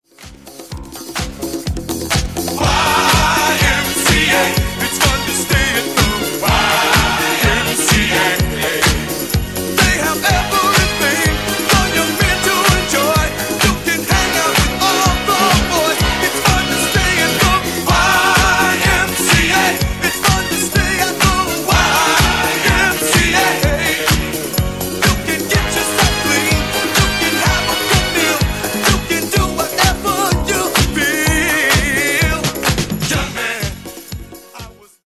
Genere:   Disco Funk